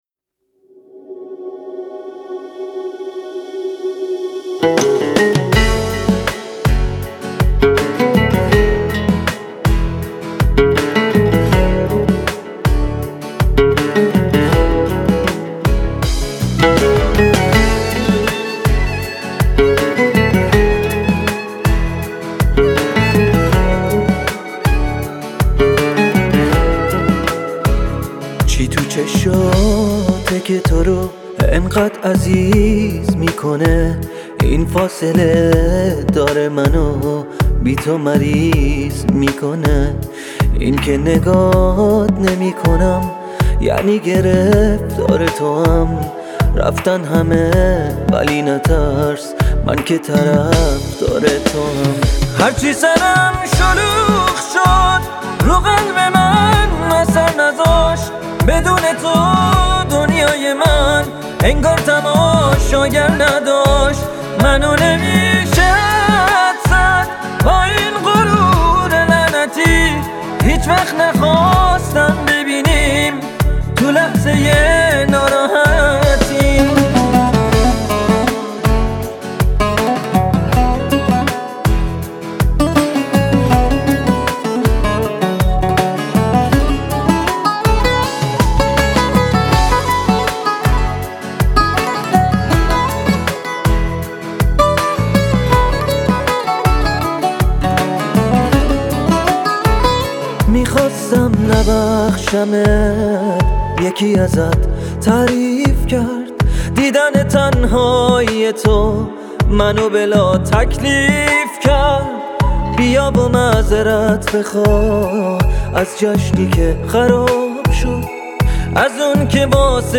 شاد و پر انرژی